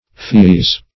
Pheese \Pheese\ (f[=e]z), v. t.